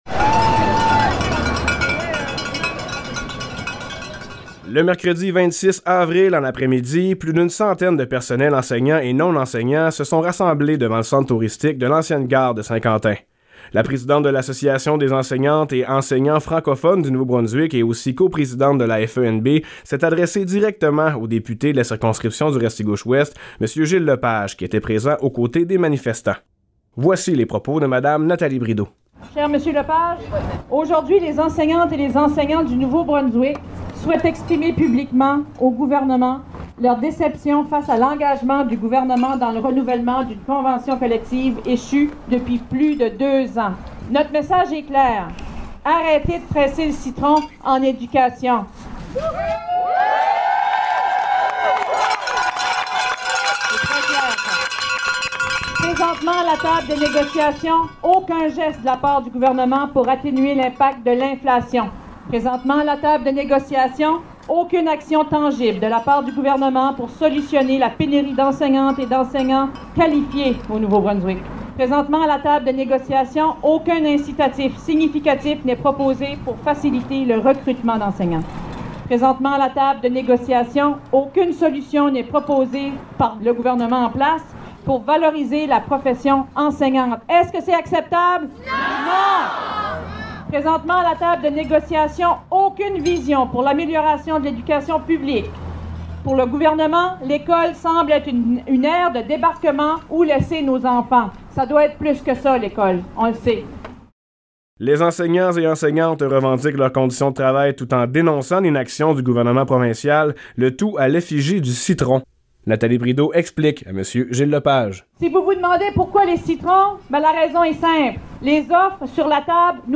sur les ondes du FM90 Route 17.